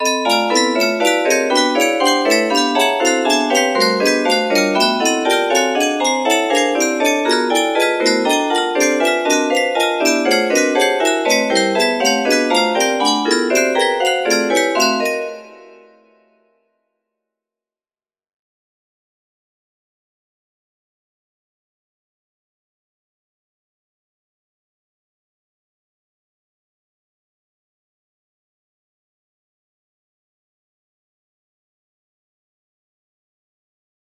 P18 music box melody